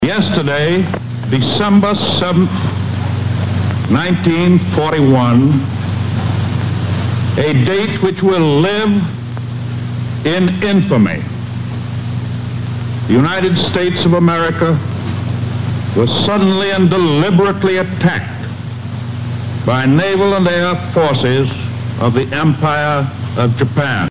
The next day, Monday, December 8th, President Franklin Delano Roosevelt addressed a joint session of Congress, asking them to declare war on Japan.
Roosevelt-to-Congress.mp3